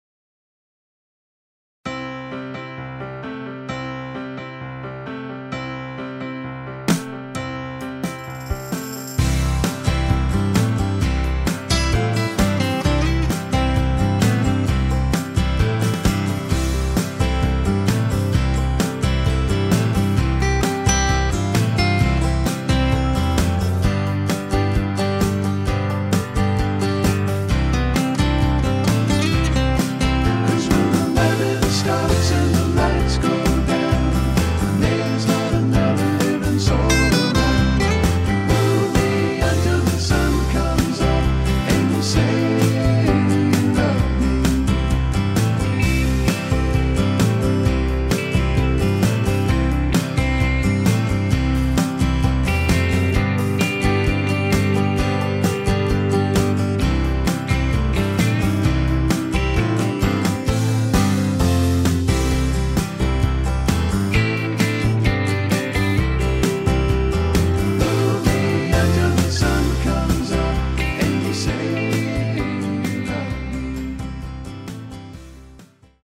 The singing call features an arrangement
who played keyboards and sang the vocal.
guitars
background vocals